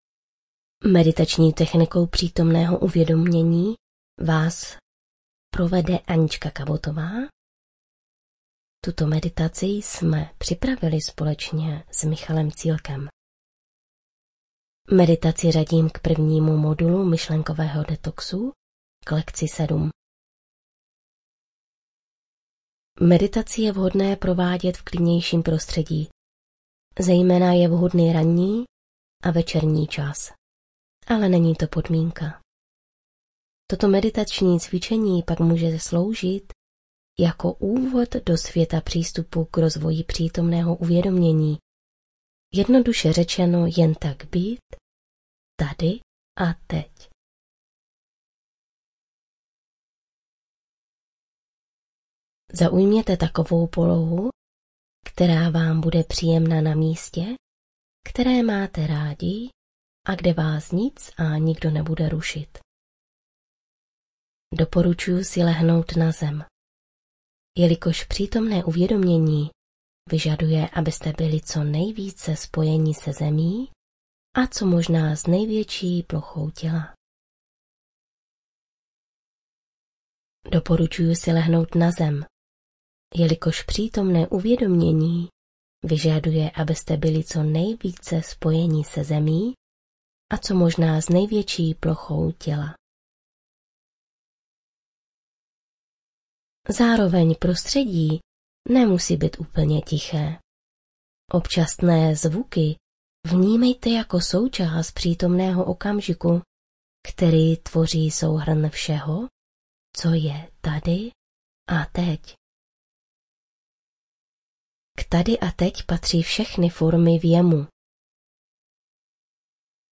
Meditace z živého vysílání ze dne 20. ZÁŘÍ 2018 – PŘÍTOMNÝ OKAMŽIK
2. MEDITACE- PŘÍTOMNÉ UVĚDOMĚNÍ (bez hudby)
2.-MEDITACE-PRITOMNEHO-UVEDOMENI-bez-hudby.mp3